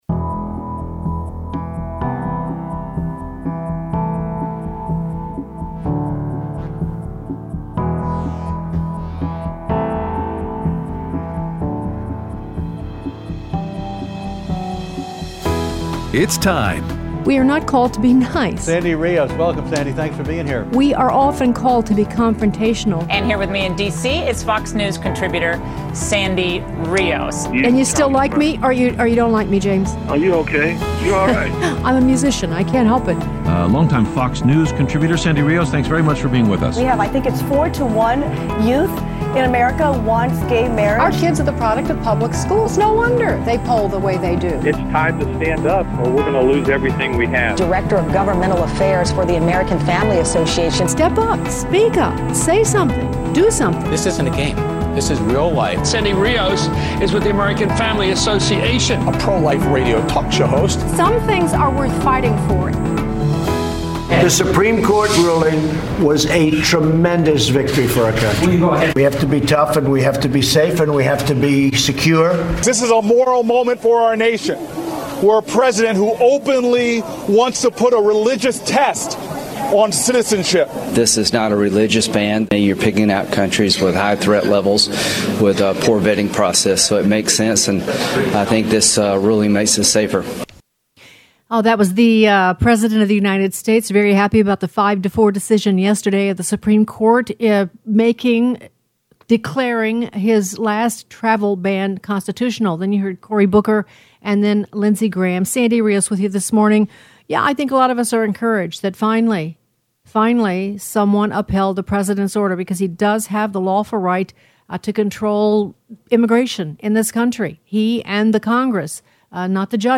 Aired Wednesday 6/27/18 on AFR 7:05AM - 8:00AM CST